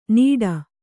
♪ nīḍa